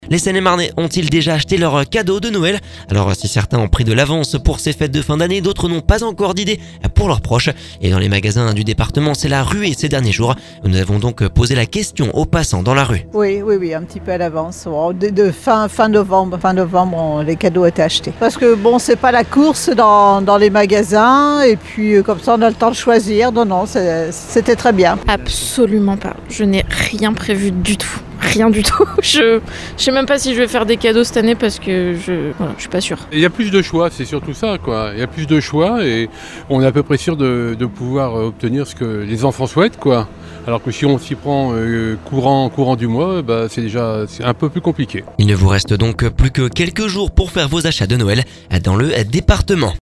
Nous avons posé la question aux passants dans la rue…